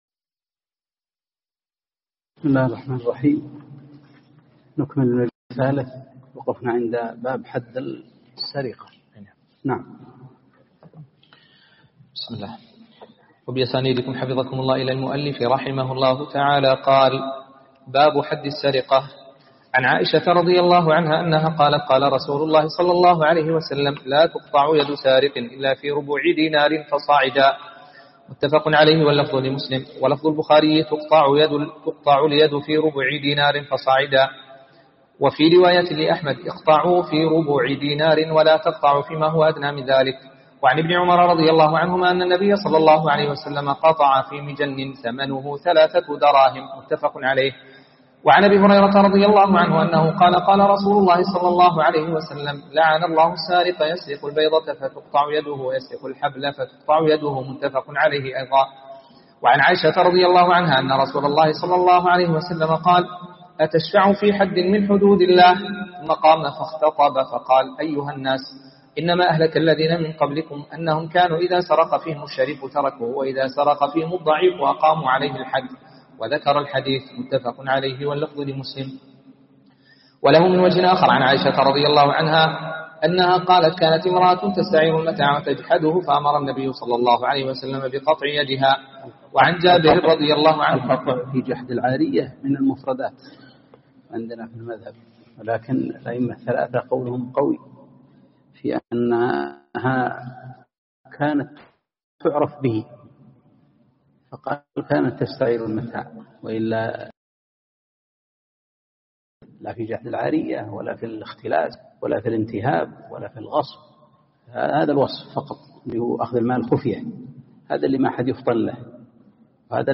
(٦ والأخير) مجلس سماع وتعليق على كتاب بلوغ المرام -باب حد السرقة